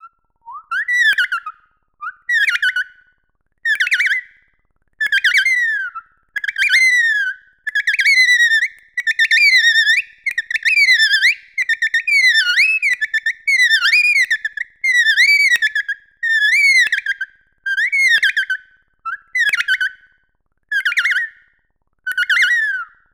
Chitter & ambiance